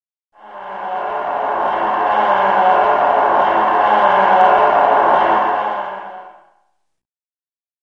sewersound.wav